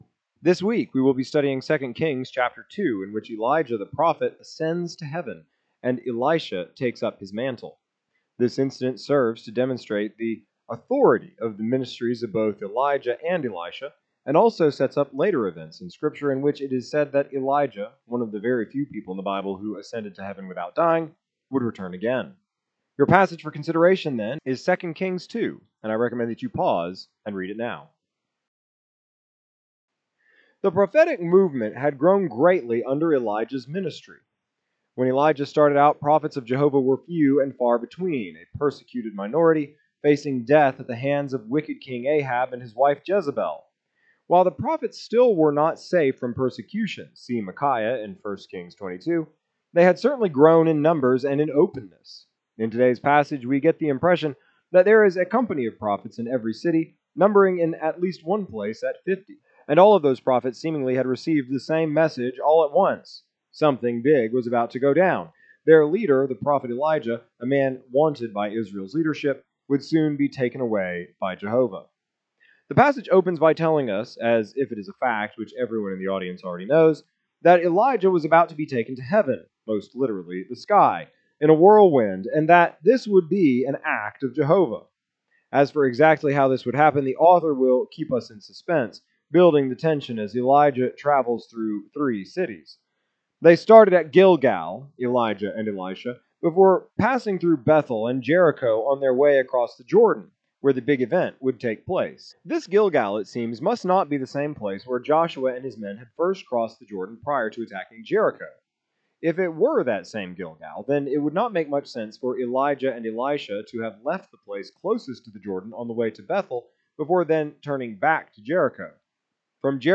exegetical sermon series